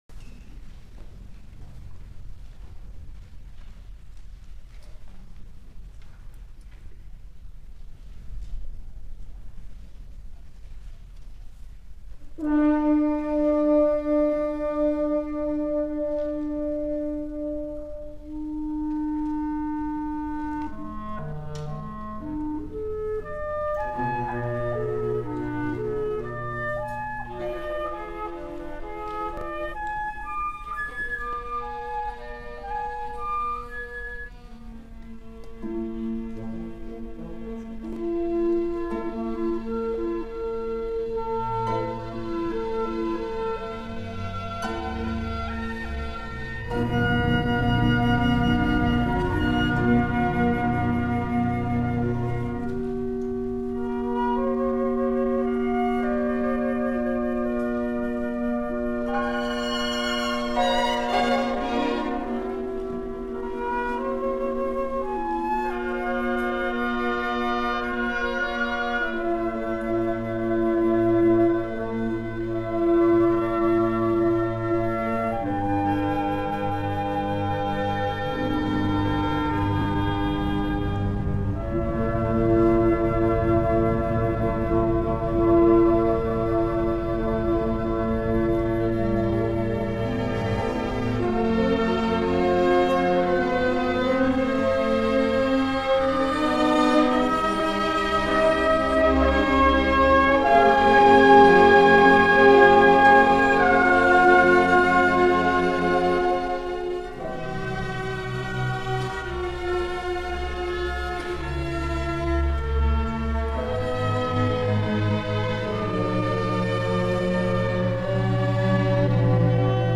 Suite for Orchestra